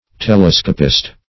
Telescopist \Te*les"co*pist\, n. One who uses a telescope.